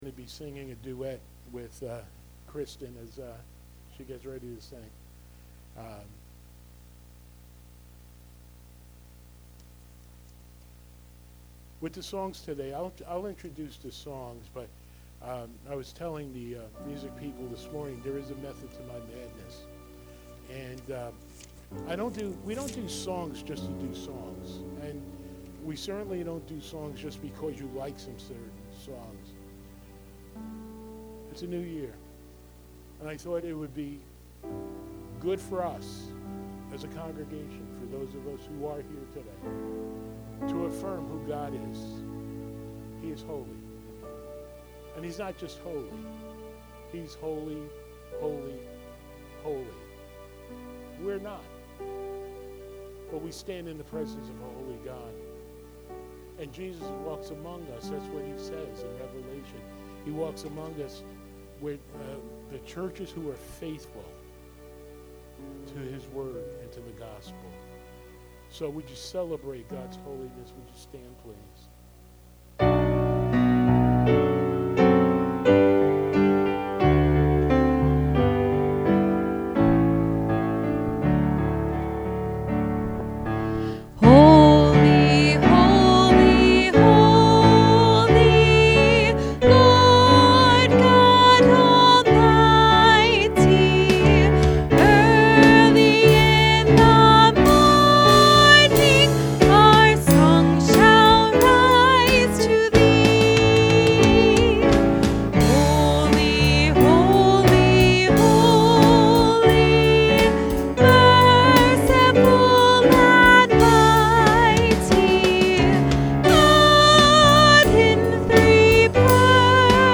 Podcast (sermons): Play in new window | Download
Series: Sunday Morning Worship Service